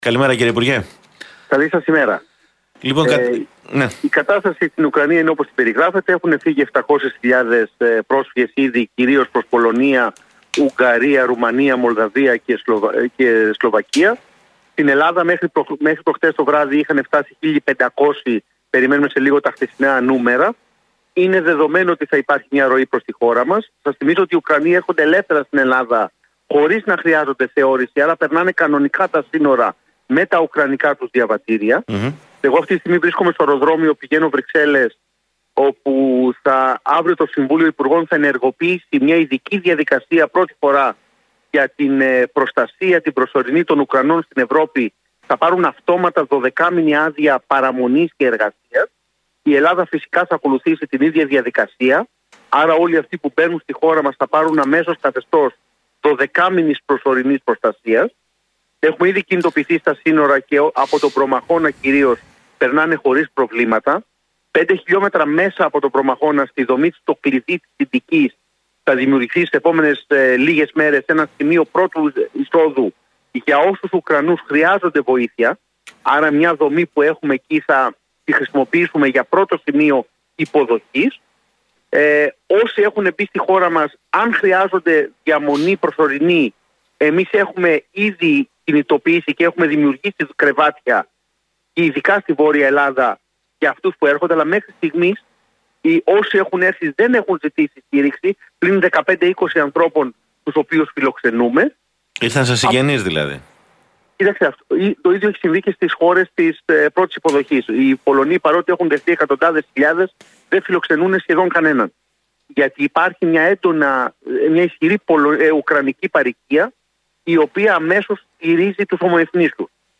Μέχρι το βράδυ της Δευτέρας είχαν φτάσει στη χώρα μας από την Ουκρανία 1.500 πρόσφυγες, δήλωσε στον Realfm 97,8 ο υπουργός Μετανάστευσης και Ασύλου, Νότης Μηταράκης.